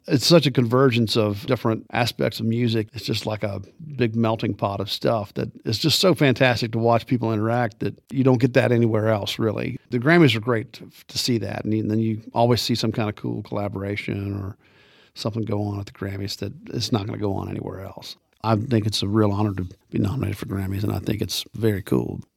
Audio / Chris Stapleton talks about the GRAMMYS.